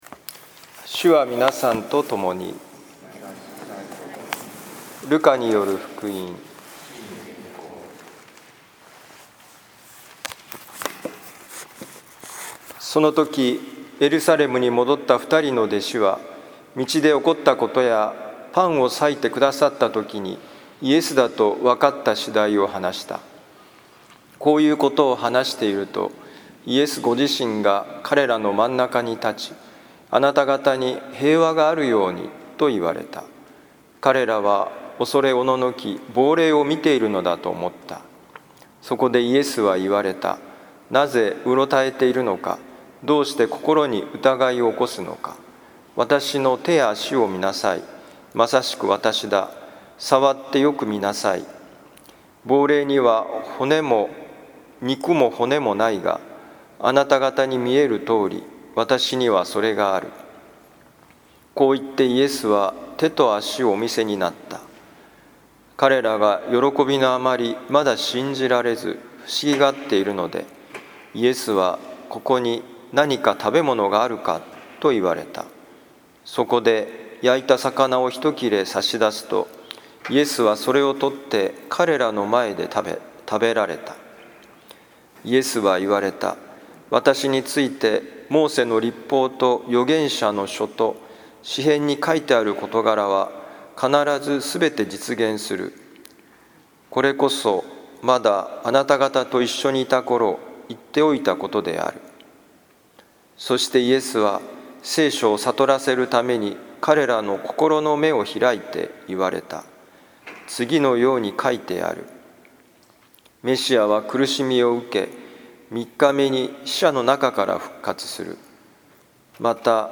ルカ福音書24章5-48節「かけ橋になる」2021年4月18日復活節第3主日改宗式のミサ聖イグナチオ教会
今日の福音書朗読とお説教の聞きどころ 復活したイエス様が現れる場面には、食事とその後のイエス様の聖書の解説がつきものです